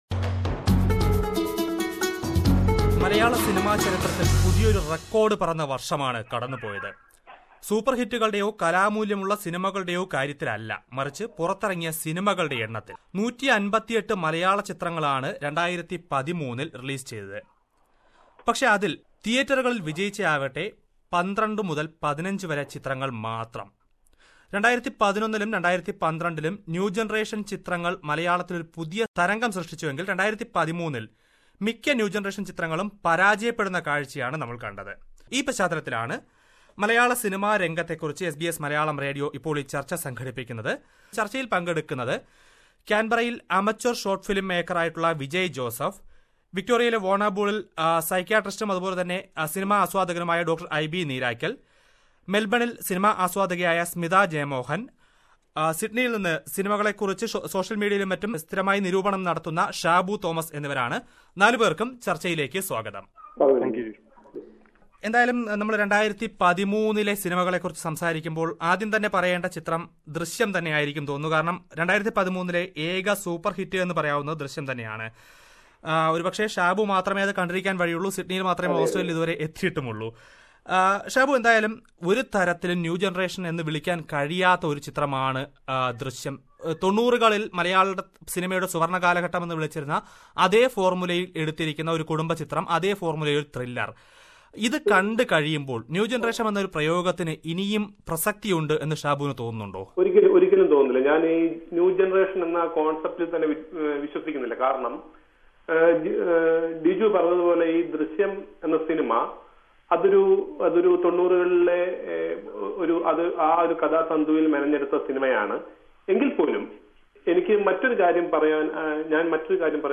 SBS Malayalam conducted a discussion with film lovers in Australia who review the changes occurred in Malayalam movies...